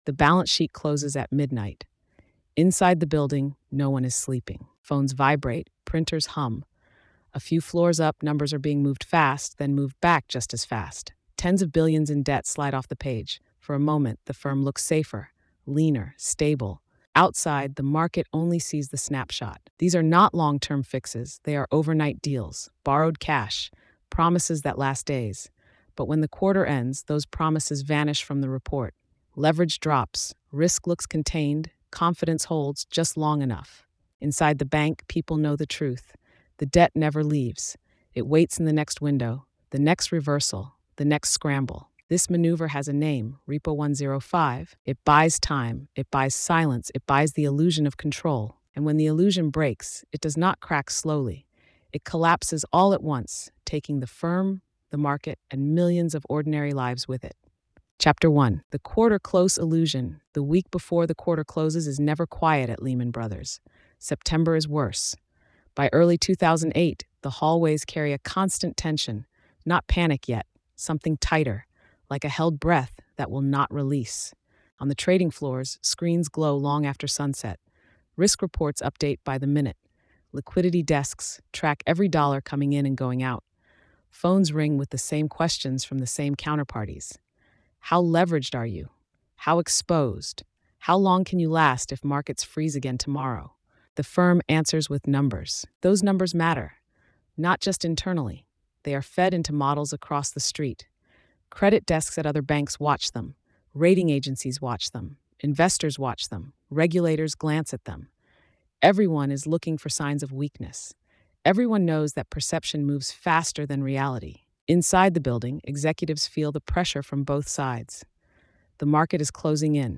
Repo one zero five: Lehman Brothers’ Disappearing Debt Trick is a hard-edged investigative narrative that examines how Lehman Brothers used short-term repo transactions to temporarily remove tens of billions of dollars in liabilities from its balance sheet in the months leading up to the two thousand eight financial collapse. Told with journalistic restraint and cinematic tension, the story exposes how timing, legal structure, and accounting treatment combined to create an illusion of stability at the exact moment the firm was most fragile.